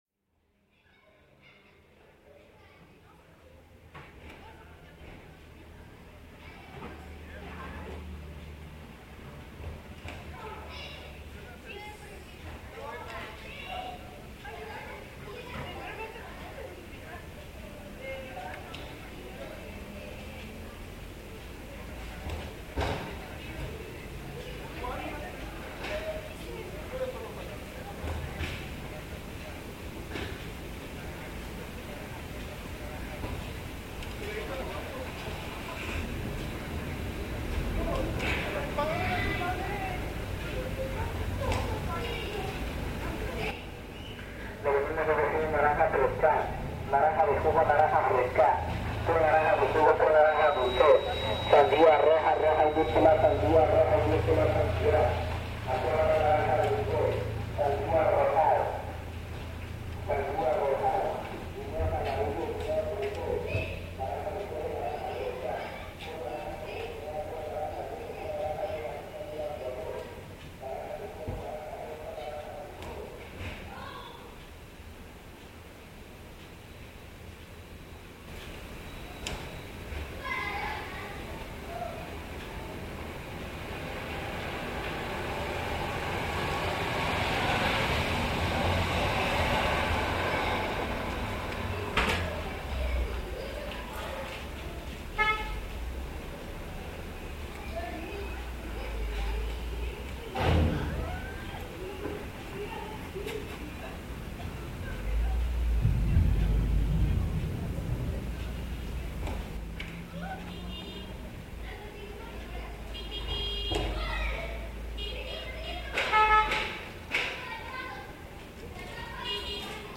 ¿Que escuchamos antes de que las gotas de lluvia invadan con su sonido las calles y el techo de lámina de las casas?
Lugar: Tuxtla Gutierrez, Chiapas; Mexico.
Equipo: Grabadora Sony ICD-UX80 Stereo